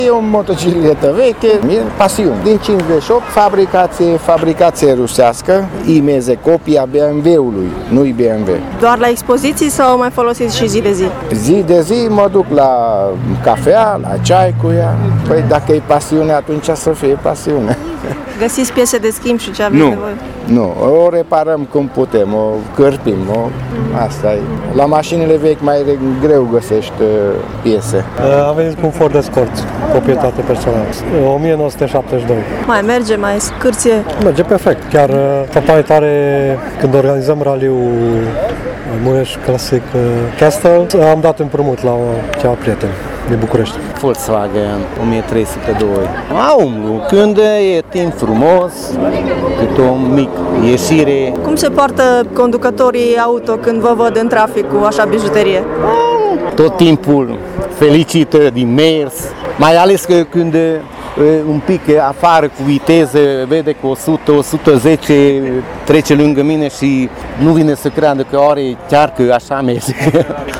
În fața Prefecturii au fost expuse 10 astfel de bijuterii pe două roți, iar trecătorii au avut ocazia să afle date despre istoria automobilului de la cei mai pasionați istorici.
Proprietarii și-au lustruit mașinile și s-au pregătit să le povestească tuturor aventurile prin care au trecut cu ele: